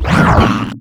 alianhit3.wav